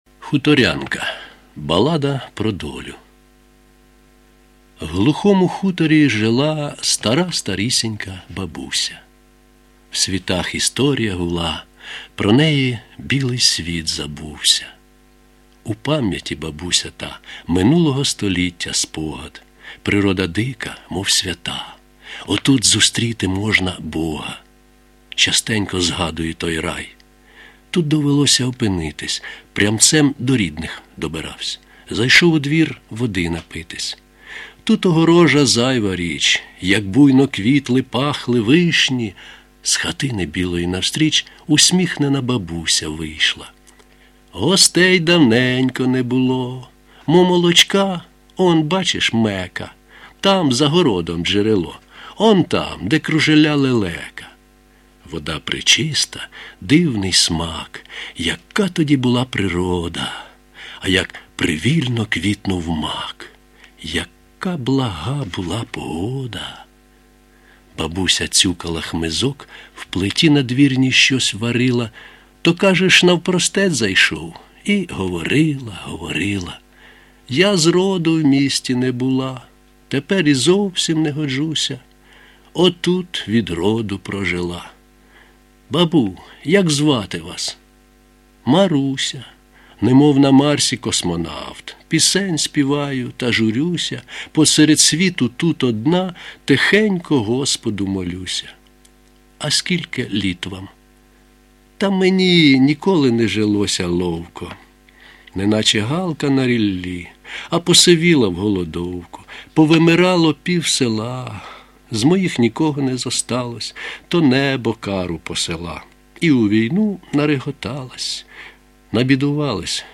Сила голосу НЕЙМОВІРНА!!!